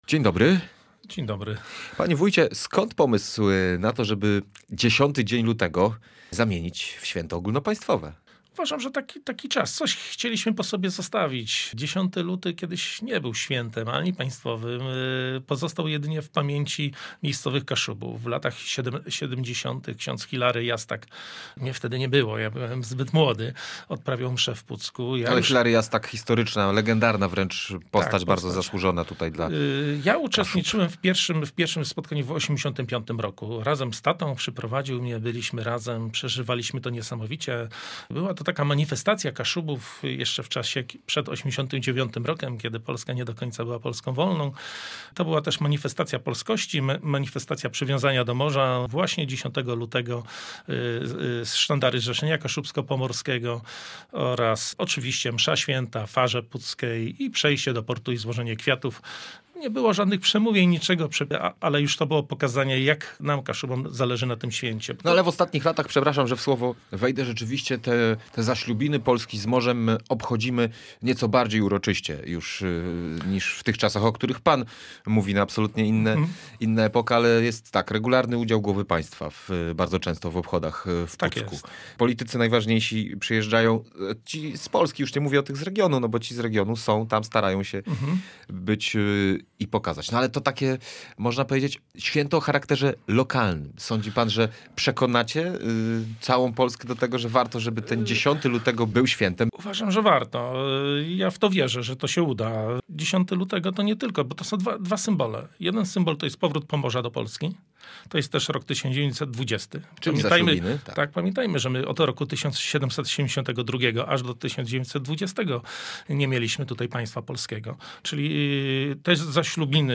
– Nie chodzi o wykreowanie kolejnego dnia wolnego od pracy – podkreślił wójt gminy Krokowa Adam Śliwicki na antenie Radia Gdańsk.